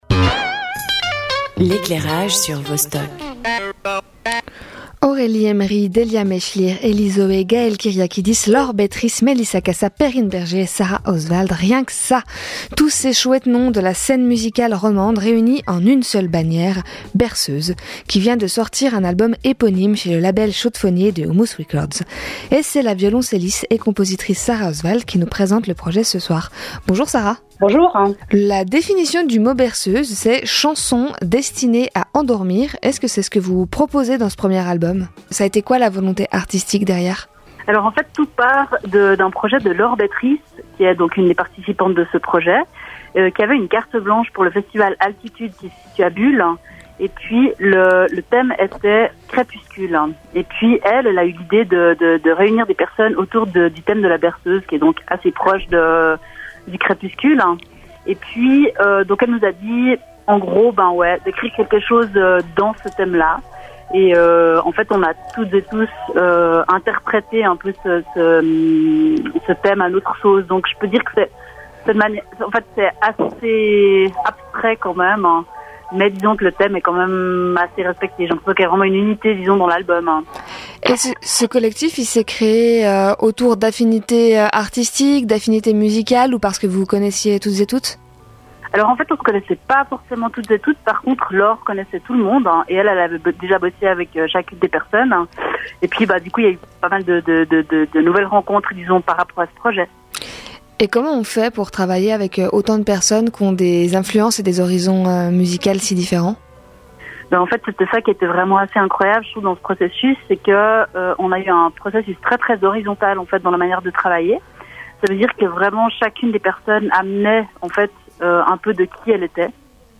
Invitée
Animation